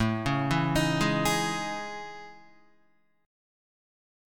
AM#11 chord